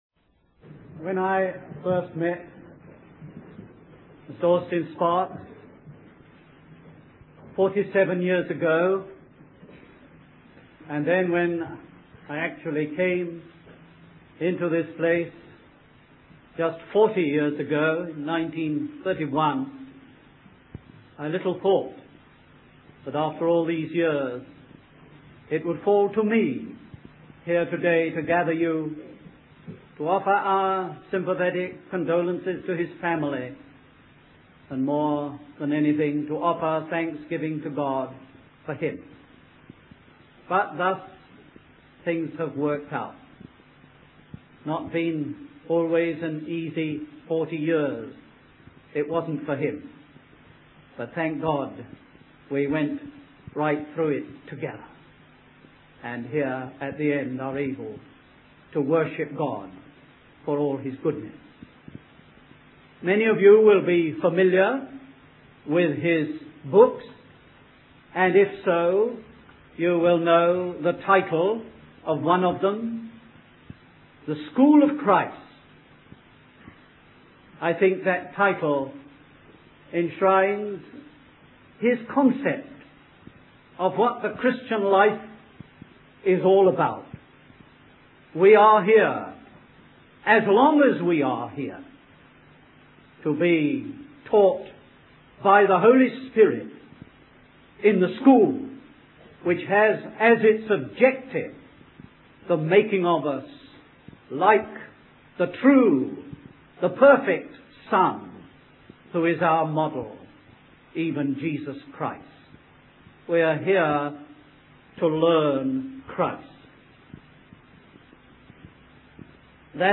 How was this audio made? Memorial Service for T. Austin-Sparks